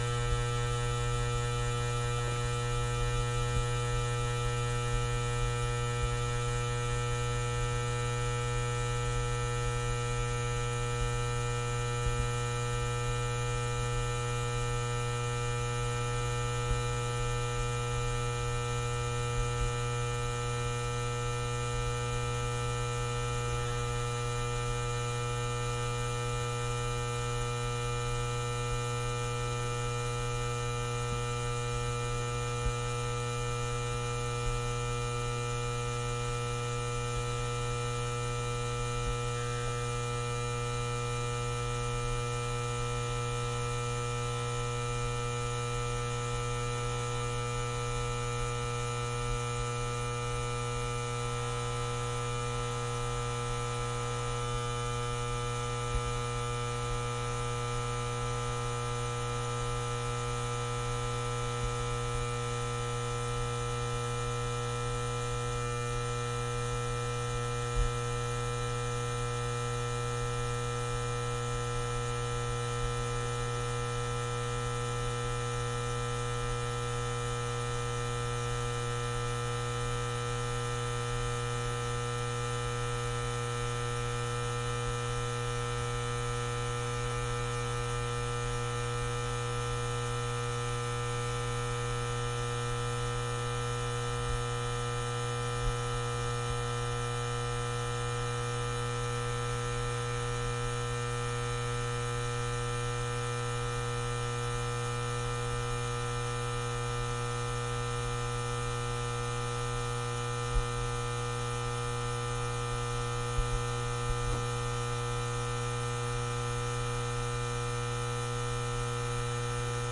随机 " 霓虹灯嗡嗡嗡立体声接近低切的味道8
描述：霓虹灯嗡嗡声嗡嗡声立体声关闭lowcut to taste8.flac